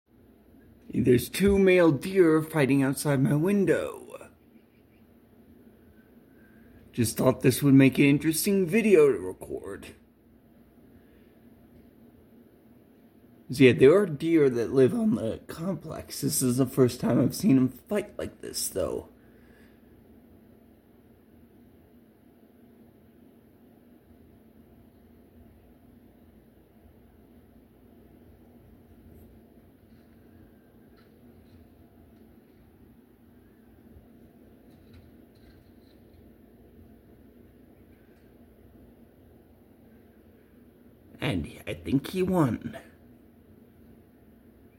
Deer Fighting Outside My Window
2 male deer fighting outside my apartment